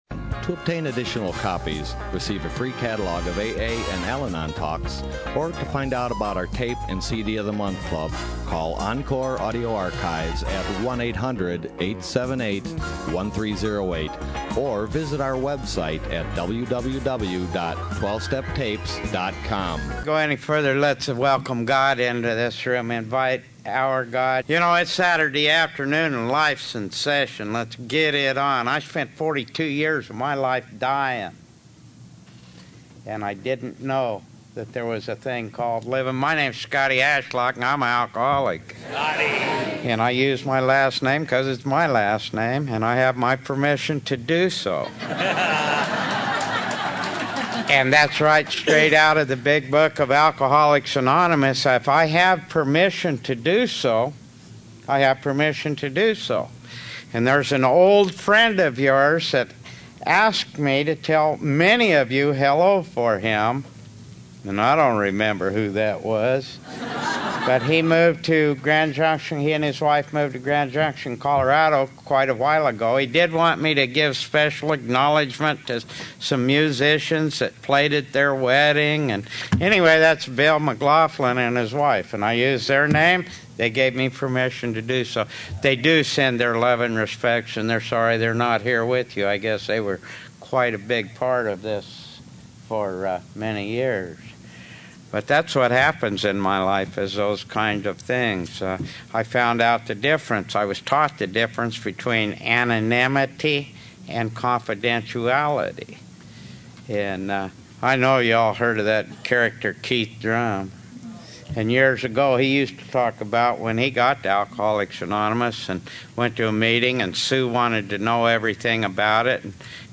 SOUTHBAY ROUNDUP 2014
BIKER MTG &#8211